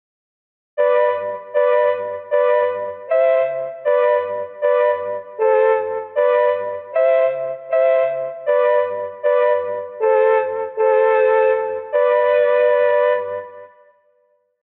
懐かしい気持ちにさせる楽器、メロトロン
4.メロトロン